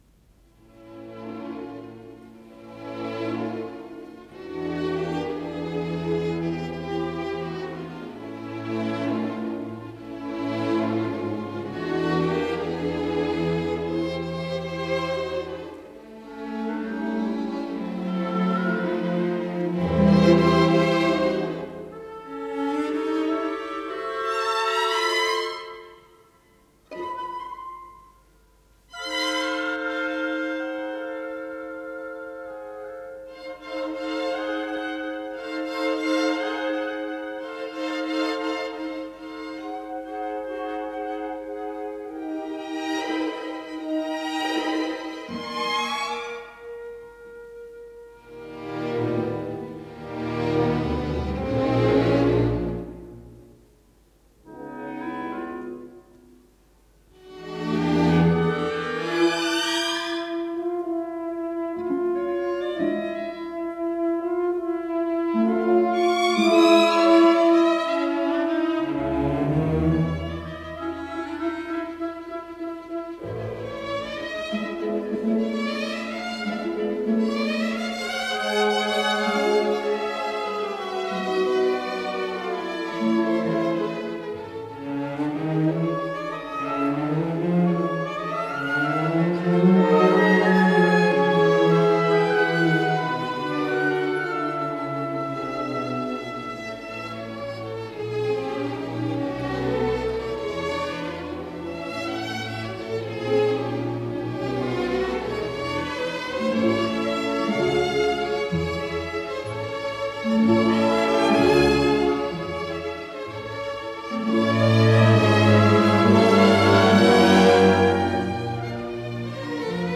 Исполнитель: Симфонический оркестр Ленинградской Государственной филармонии
Название передачи Эолиды Подзаголовок Симфоническая поэма, ля мажор Код П-0263 Фонд Норильская студия телевидения (ГДРЗ) Редакция Музыкальная Общее звучание 00:10:15 Дата переписи 31.10.1968 Дата добавления 29.04.2025 Прослушать